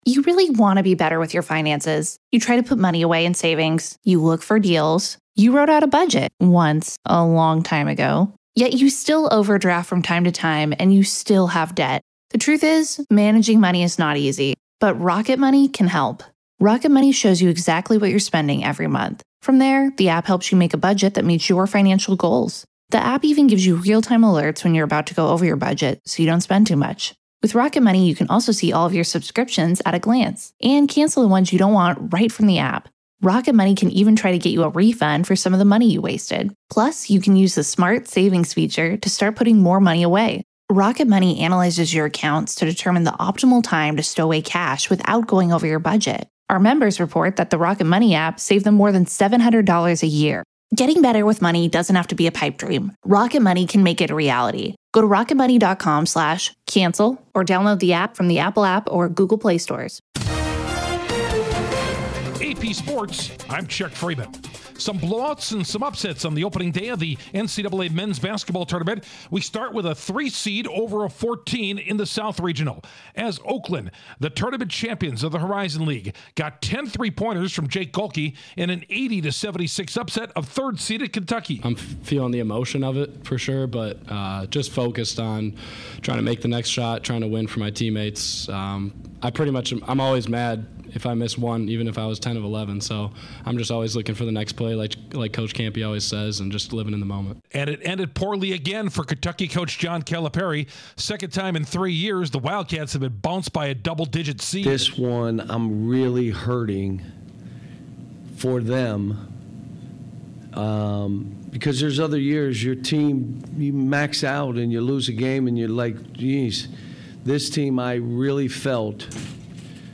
The latest in sports